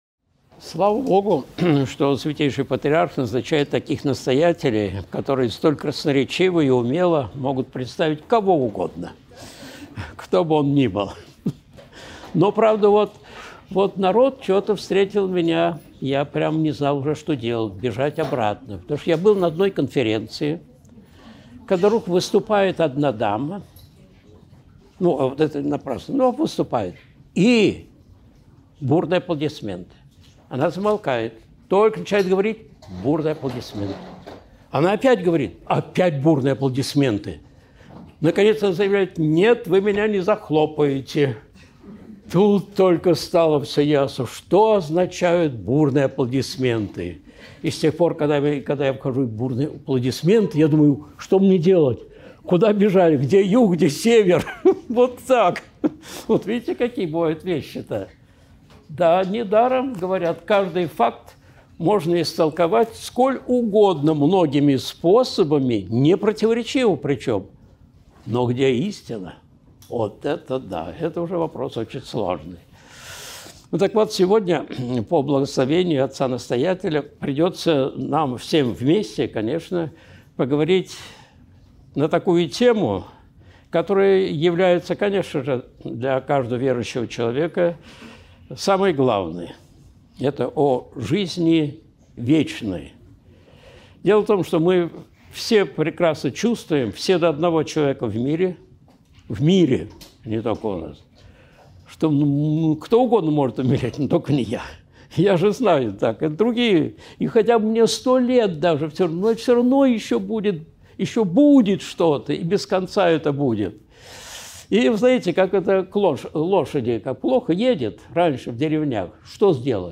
Надо, наконец, принять решение! (Храм Рождества Богородицы в Старом Симонове, 12.02.2023)